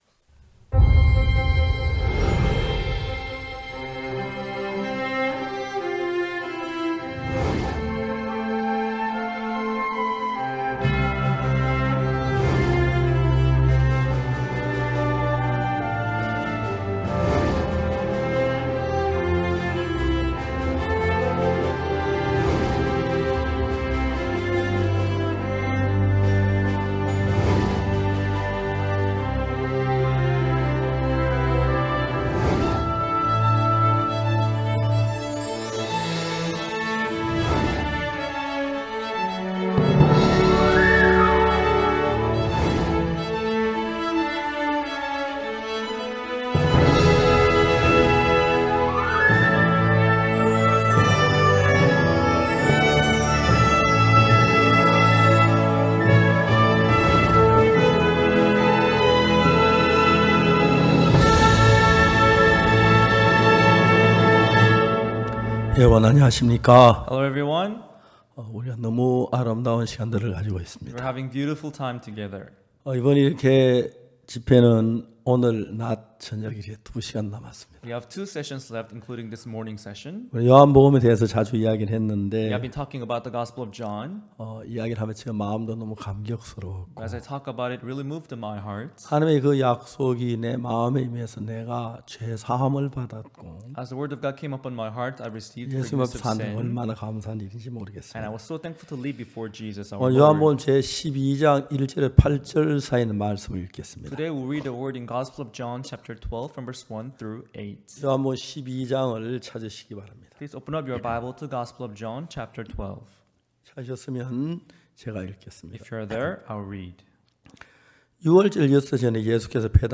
2021 후반기 온라인성경세미나 #8 예수님께 향유를 부은 마리아의 마음 좋아요 즐겨찾기 프로그램 소개 프로그램 응원 공유 다운로드 태그 이 콘텐츠를 보시고 떠오르는 단어는 무엇입니까?